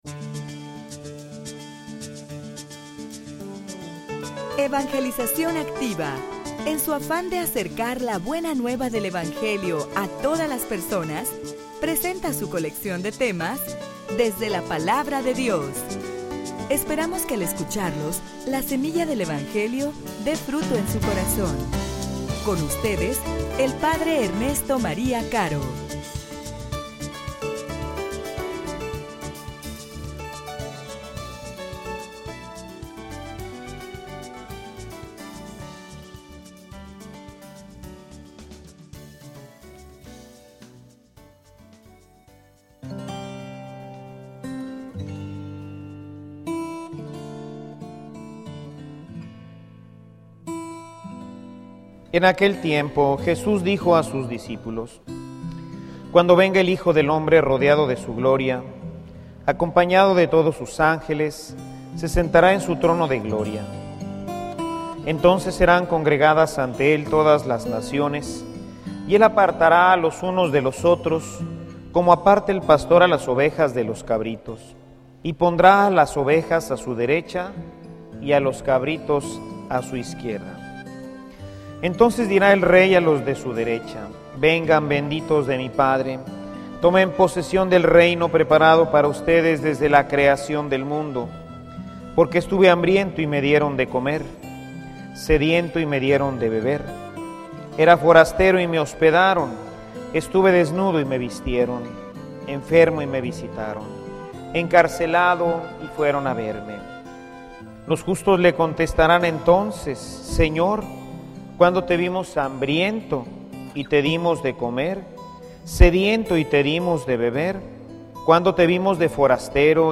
homilia_No_pierdas_tu_boleto.mp3